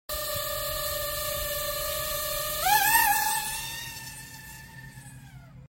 DJI FPV sound (sport mode sound effects free download
DJI FPV sound (sport mode only)